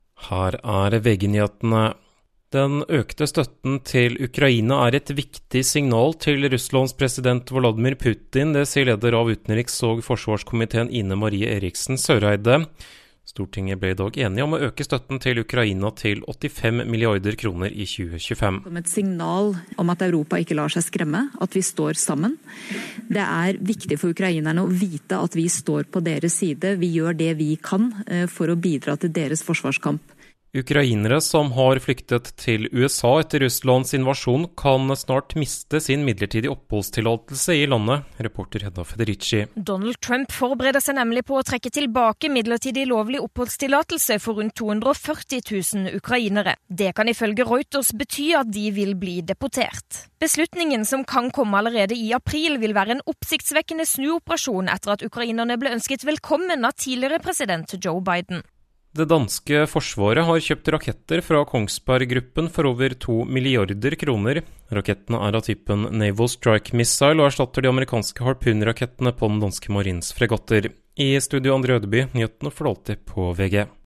1 Siste nytt fra VG 1:14 Play Pause 2h ago 1:14 Play Pause Reproducir más Tarde Reproducir más Tarde Listas Me gusta Me gusta 1:14 Hold deg oppdatert med ferske nyhetsoppdateringer på lyd fra VG.